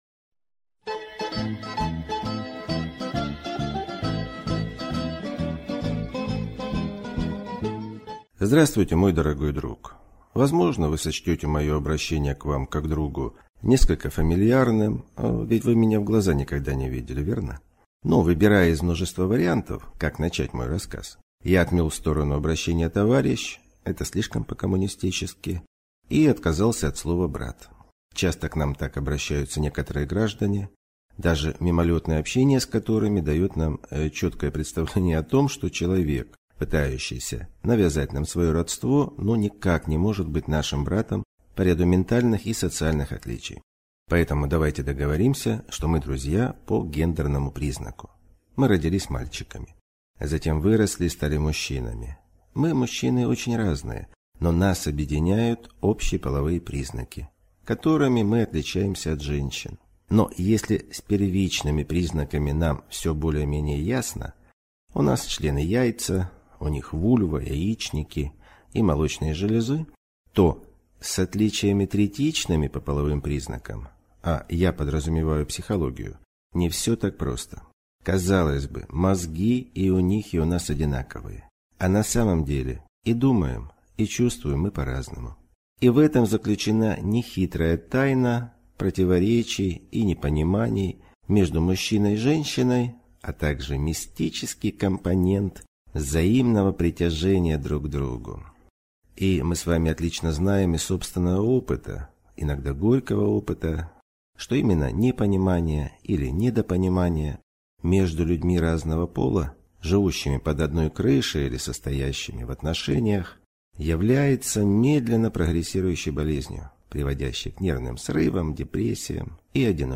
Аудиокнига Женщина. Просто о сложном | Библиотека аудиокниг